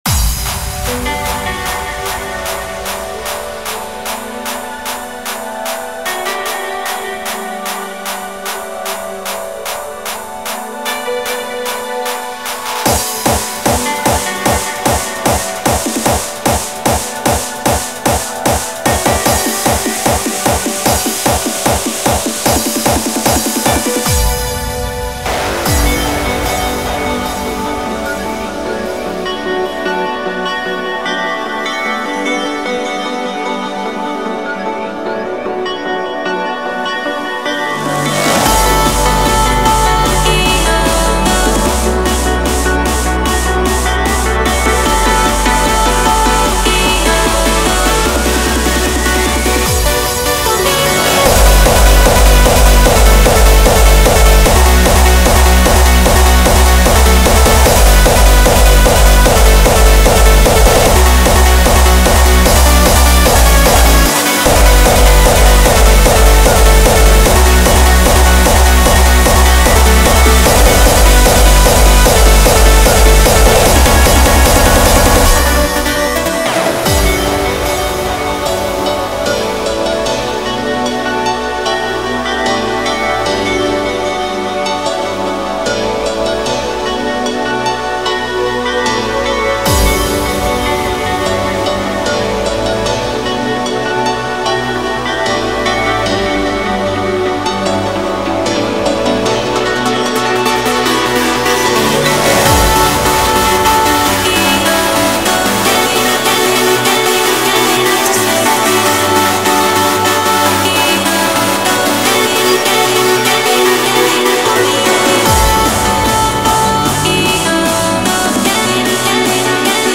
Index of: /data/localtracks/Hardstyle/